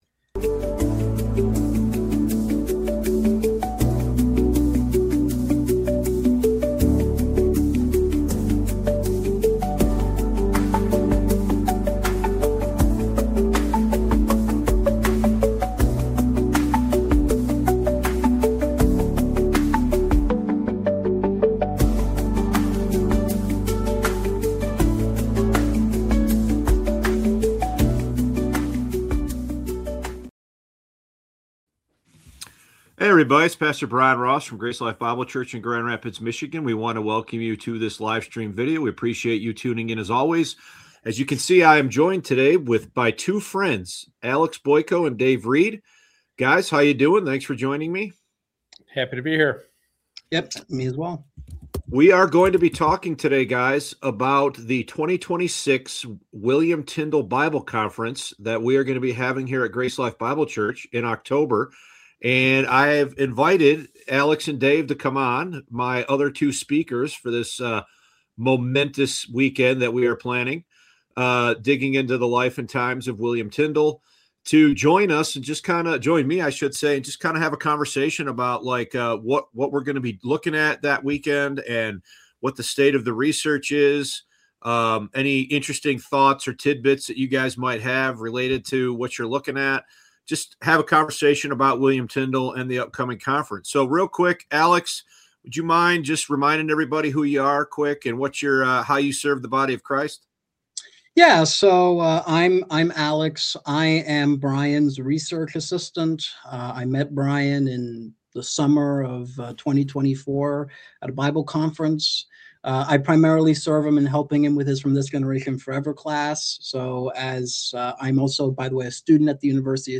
2026 Tyndale Conference: A Discussion